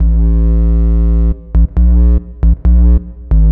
BL 136-BPM 2-A#.wav